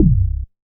MoogLoFilt 002.WAV